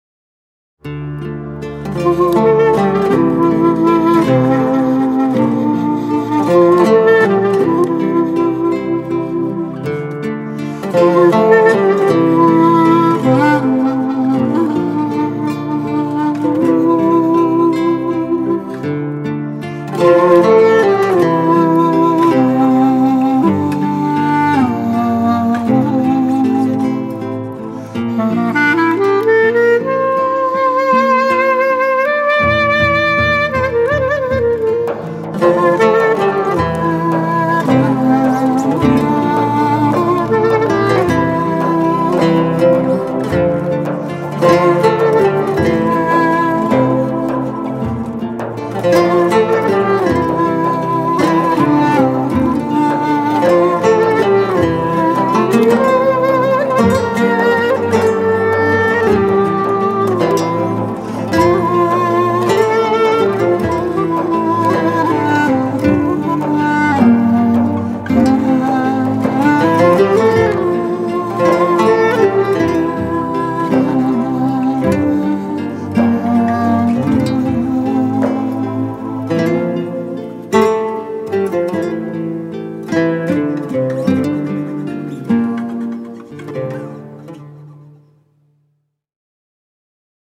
tema dizi müziği, duygusal hüzünlü üzgün fon müziği.